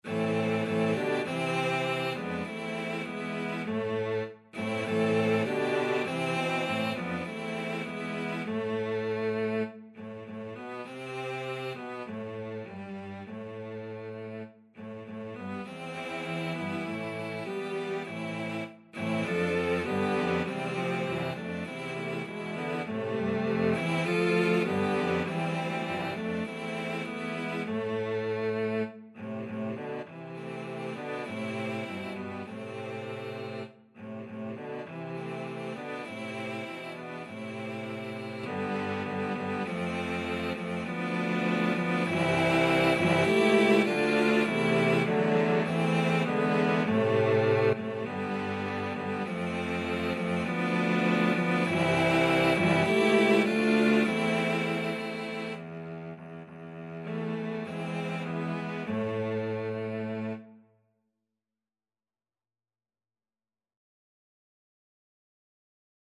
Free Sheet music for Cello Quartet
2/4 (View more 2/4 Music)
Moderato
A minor (Sounding Pitch) (View more A minor Music for Cello Quartet )
Classical (View more Classical Cello Quartet Music)